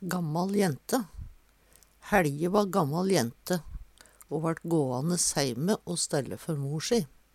gammal jente - Numedalsmål (en-US)